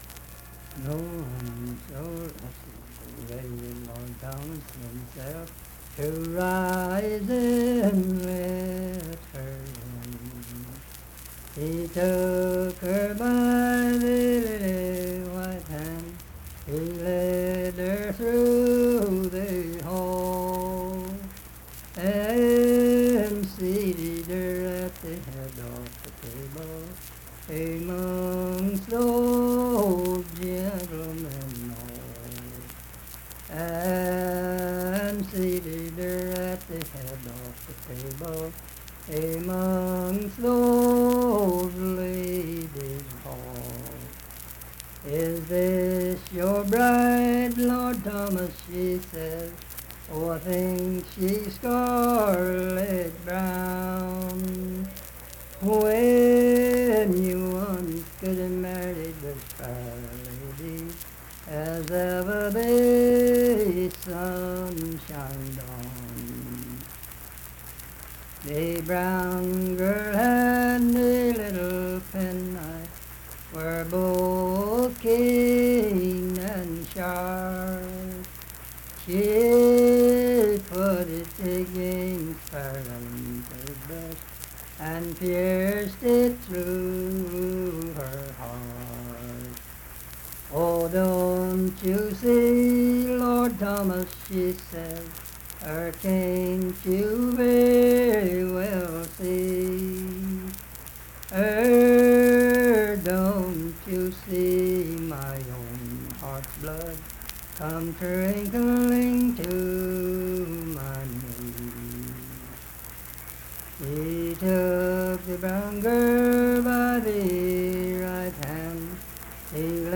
Unaccompanied vocal music
Verse-refrain, 10(4).
Voice (sung)
Harts (W. Va.), Lincoln County (W. Va.)